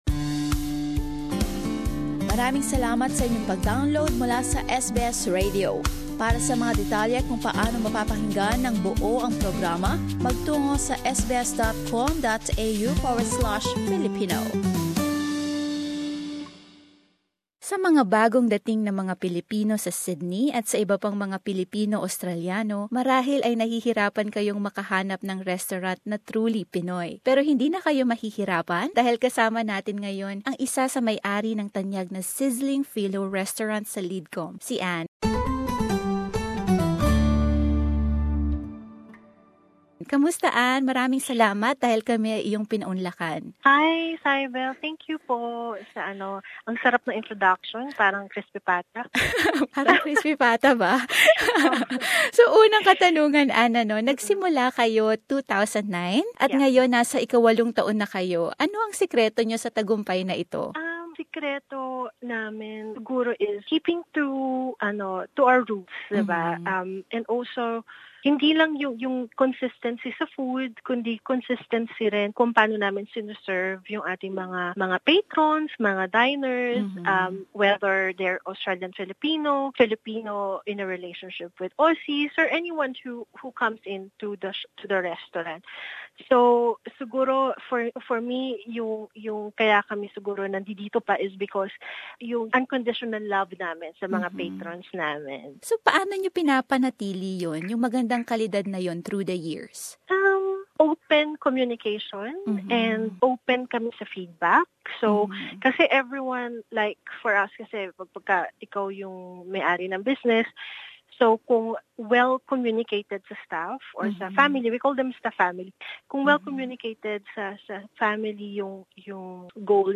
Sa ikalawang bahagi ng panayam